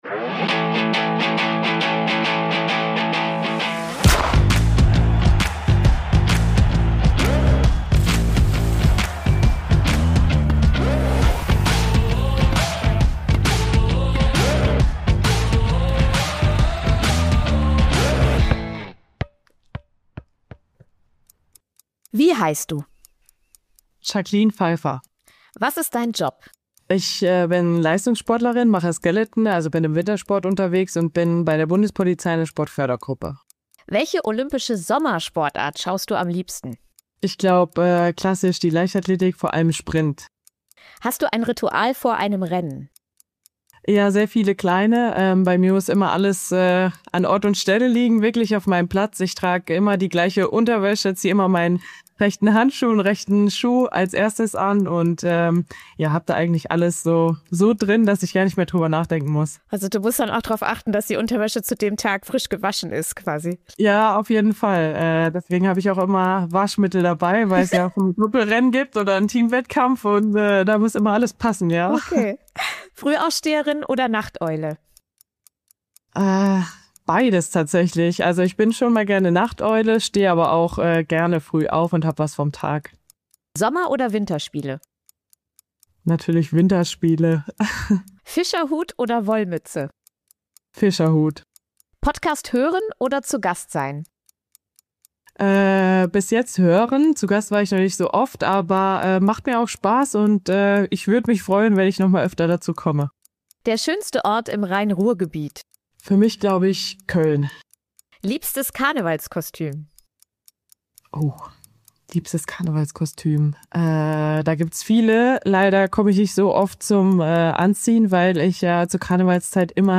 Diesmal zu Gast: Skeleton-Pilotin Jacqueline Pfeifer, dreimalige Medaillengewinnerin bei Olympischen Winterspielen dabei.